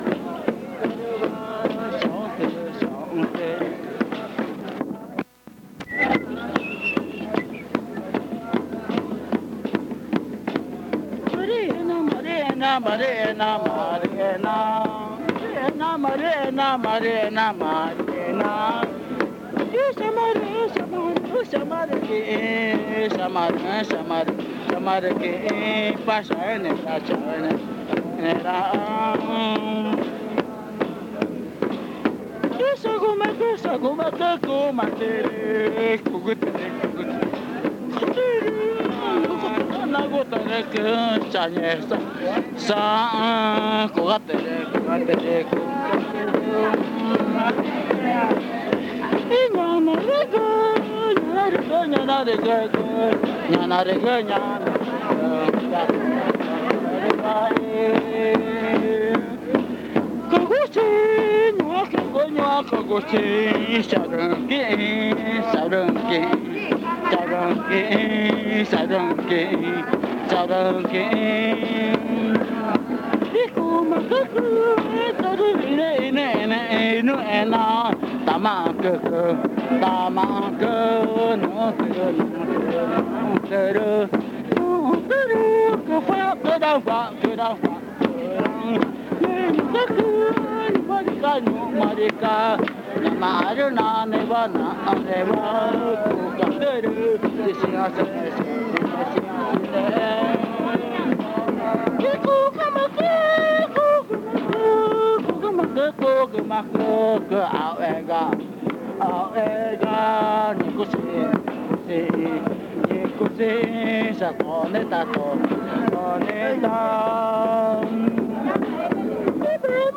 El casete contiene cantos del ritual de la pubertad magütá.
El audio publicado contiene los lados A y B del casete. El lado A presente problemas de grabación a partir del minuto 00:30:00 aproximadamente.